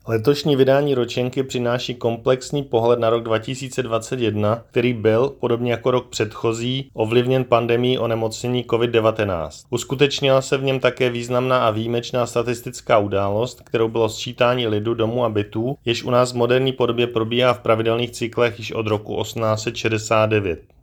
Vyjádření Marka Rojíčka, předsedy ČSÚ, soubor ve formátu MP3, 816.37 kB